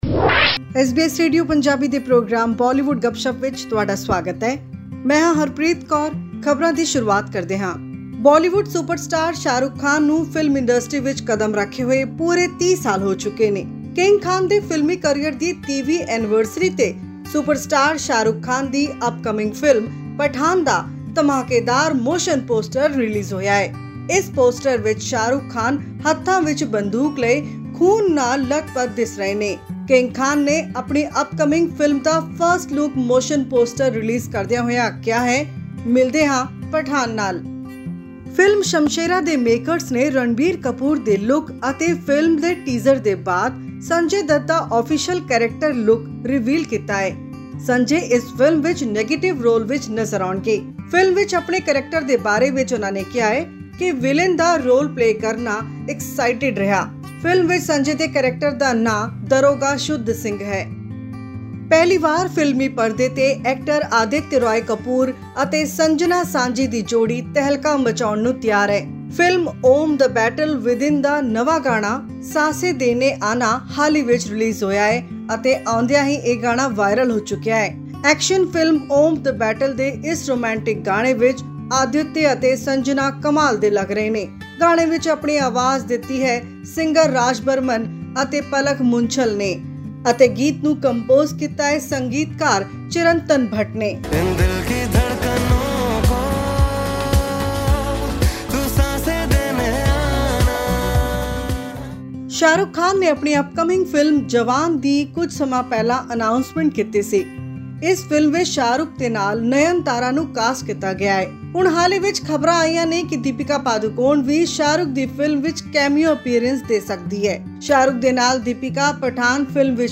Celebrating his 30th anniversary in Bollywood, Shah Rukh Khan has released the poster of his upcoming movie Pathan. To get an update on the new movie and song releases, listen to our weekly news bulletin from Bollywood.